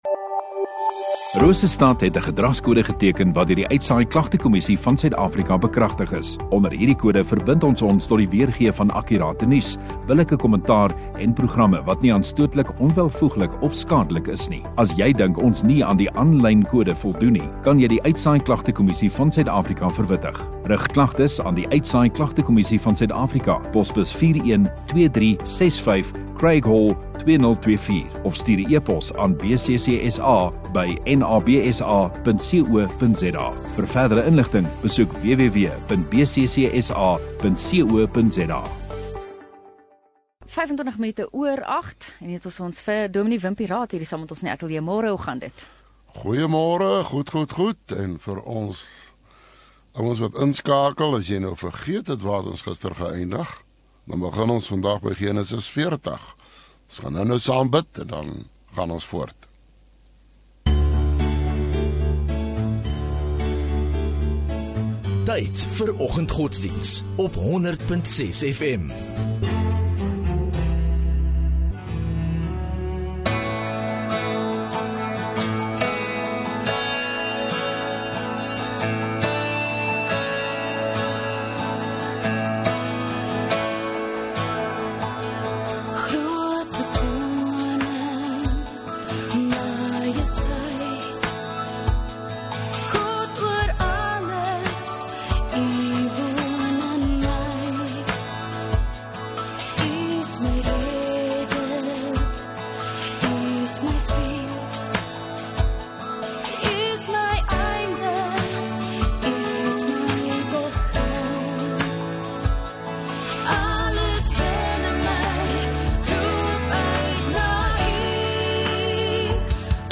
13 Jun Donderdag Oggenddiens